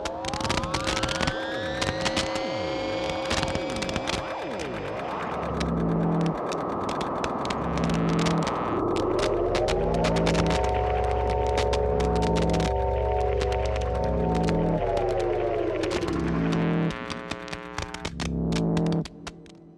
electromagnetic-plant-loop.ogg